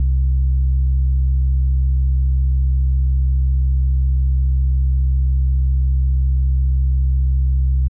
mnl-solid step triangle wave.wav